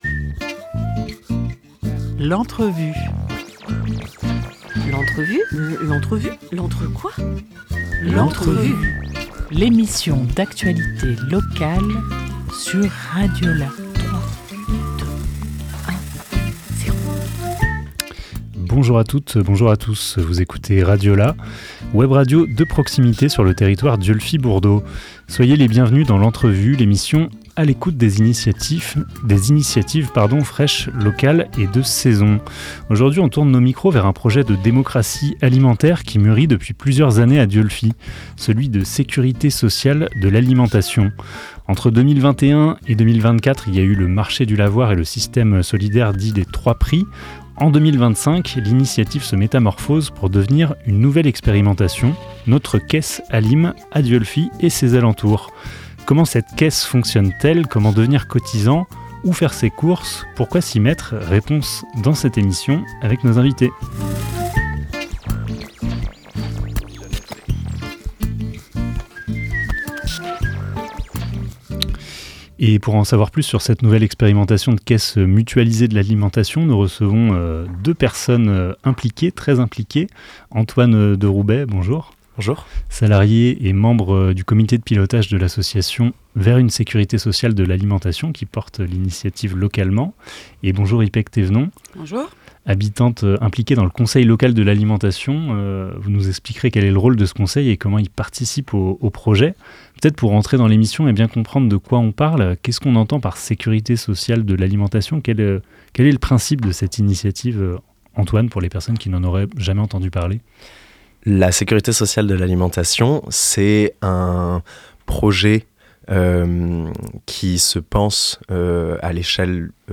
11 février 2025 11:30 | Interview